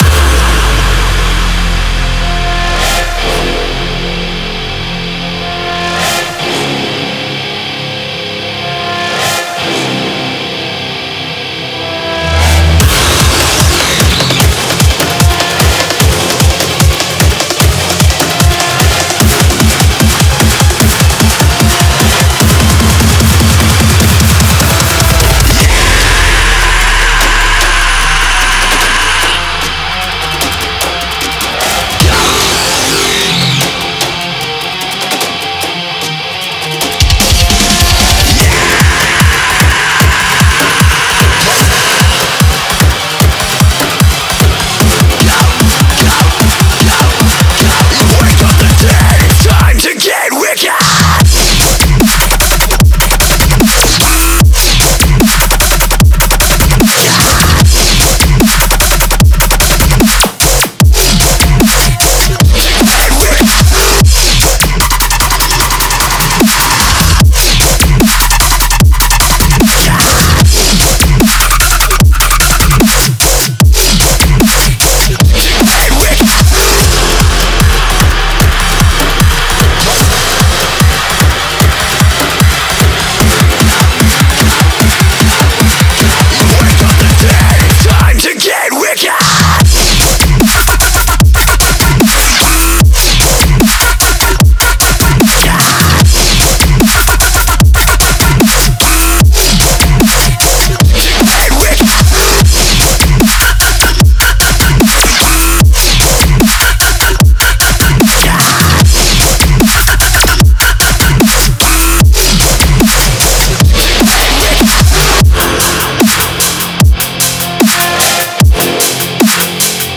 BPM150
Audio QualityPerfect (High Quality)
Comentarios[BROSTEP]